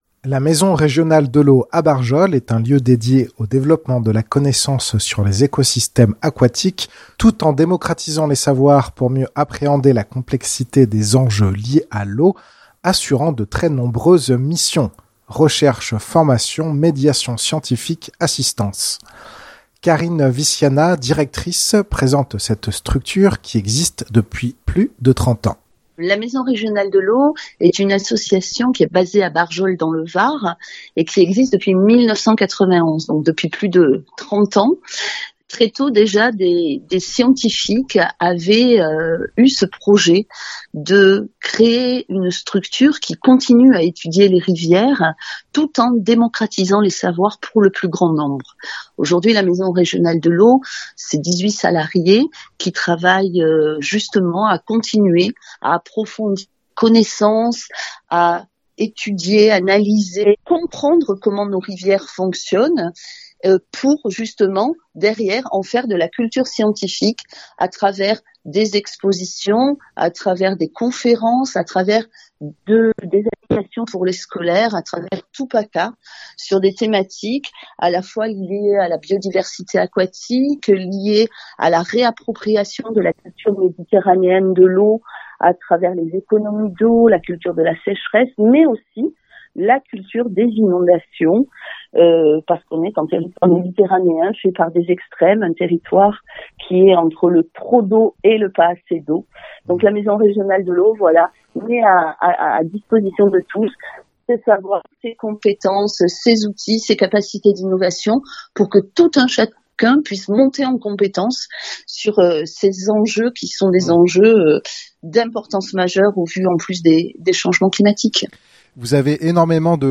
Reportage